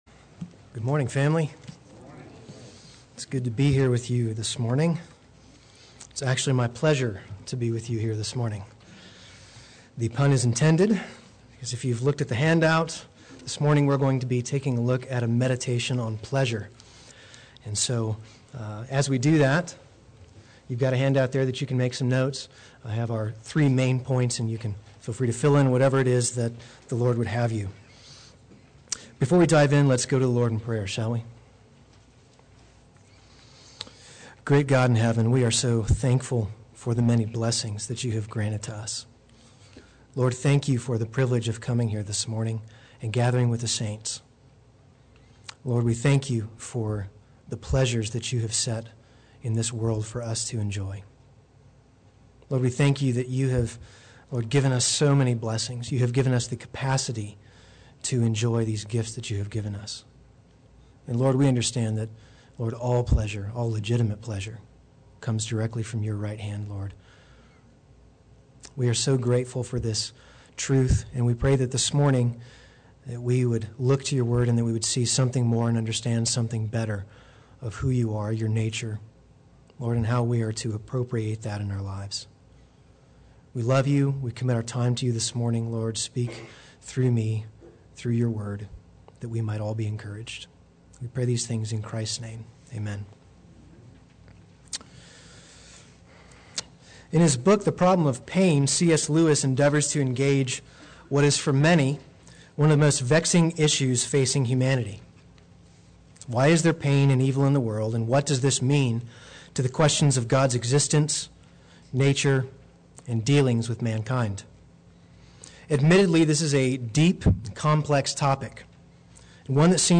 Play Sermon Get HCF Teaching Automatically.
A Biblical Meditation on Pleasure Sunday Worship